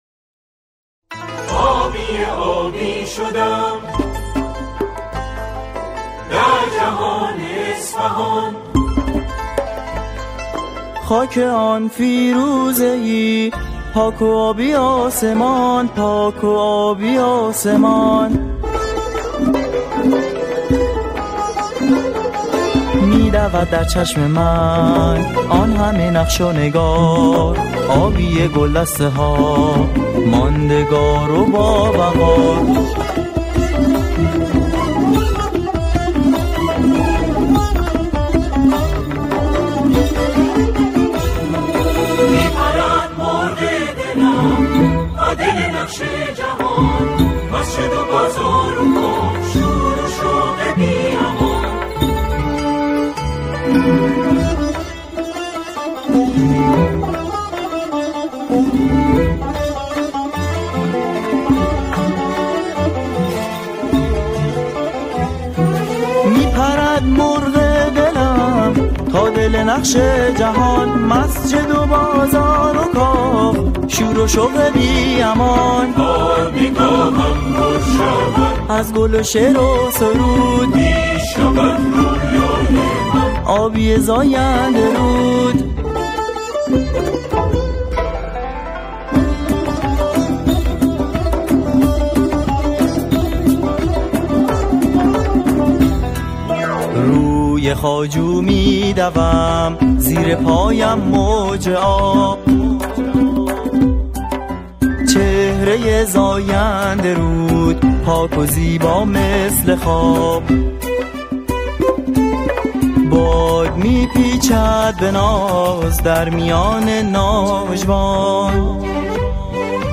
سرودهای شهرها و استانها